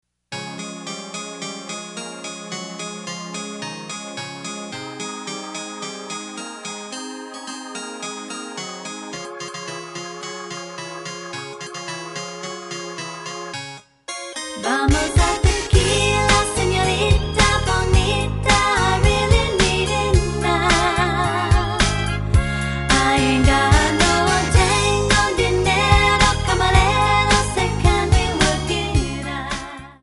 Backing track files: 1990s (2737)